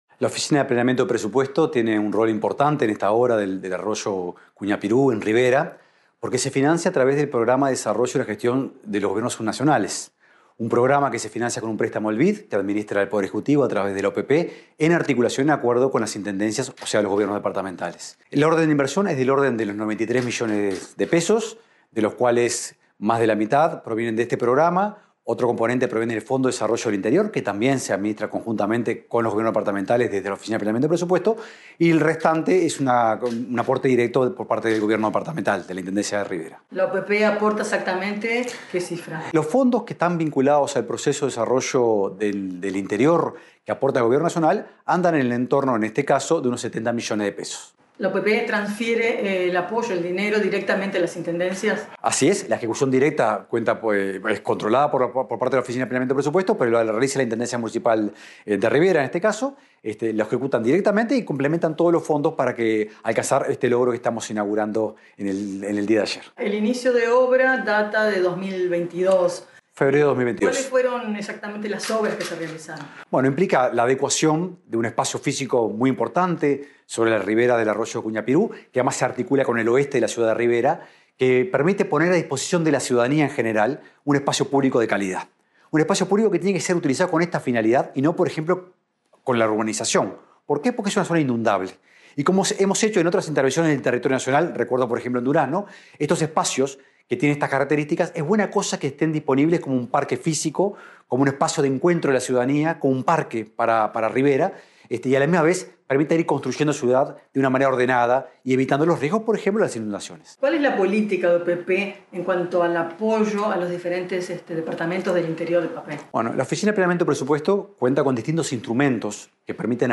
Declaraciones del director de OPP, Rodrigo Arim